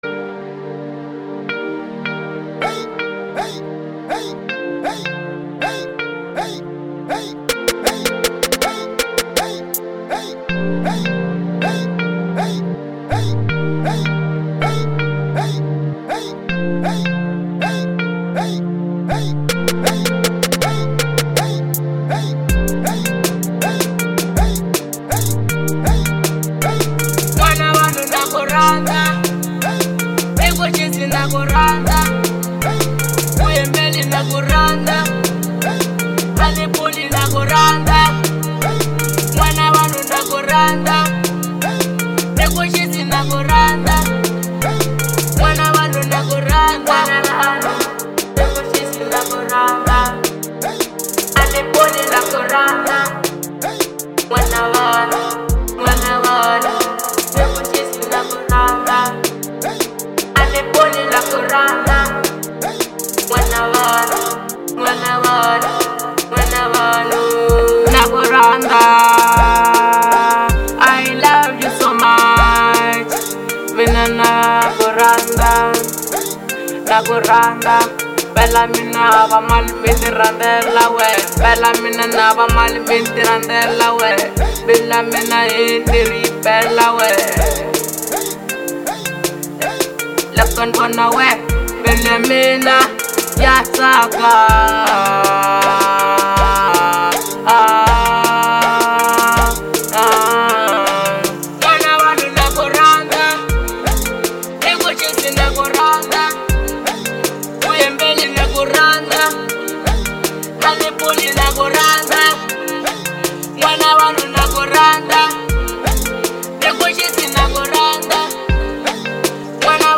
04:19 Genre : Trap Size